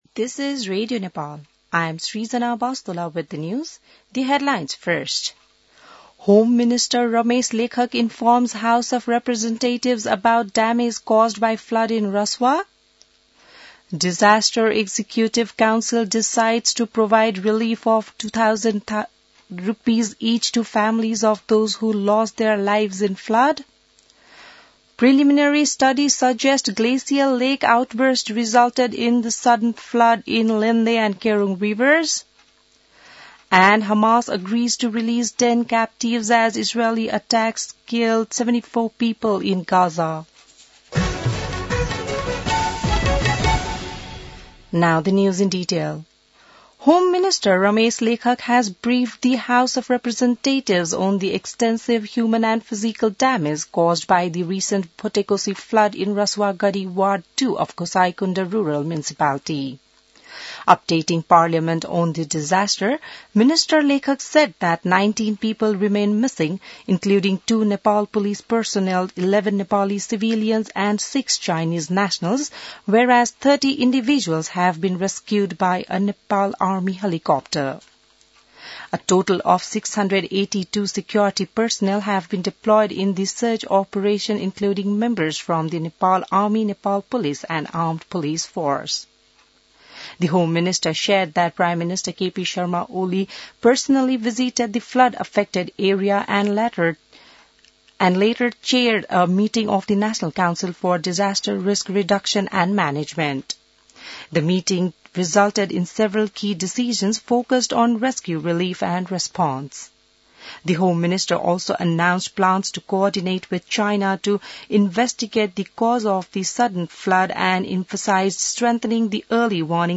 बिहान ८ बजेको अङ्ग्रेजी समाचार : २६ असार , २०८२